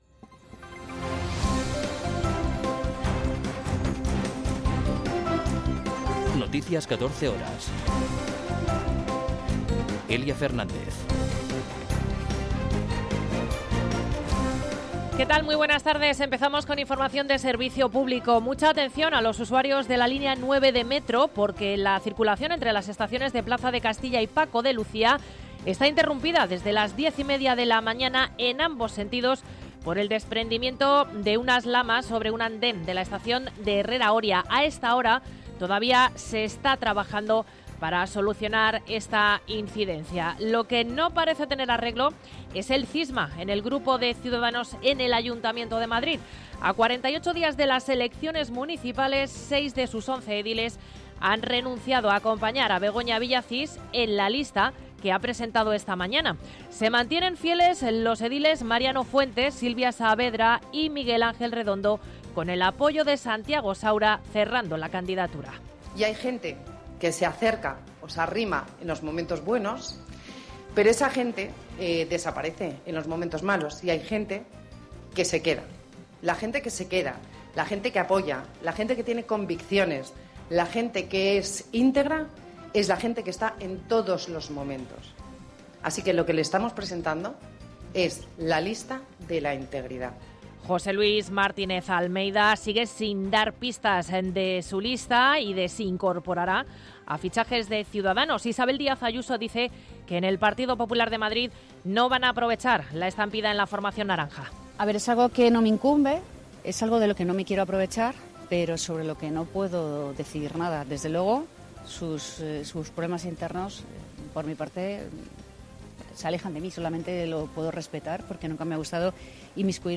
Noticias 14 horas 11.04.2023